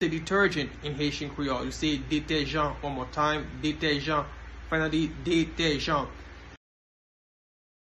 Listen to and watch “Detèjan” pronunciation in Haitian Creole by a native Haitian  in the video below:
Detergent-in-Haitian-Creole-Detejan-pronunciation-by-a-Haitian-teacher.mp3